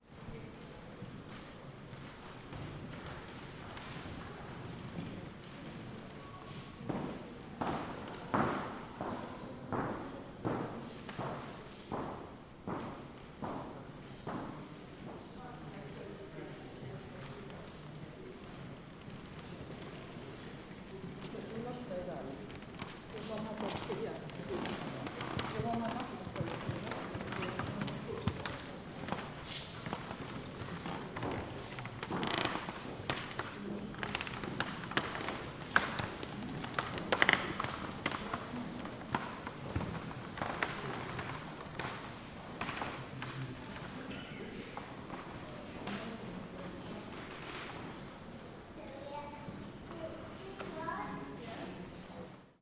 Атмосфера Национальной галереи в Лондоне, обстановка комнаты 2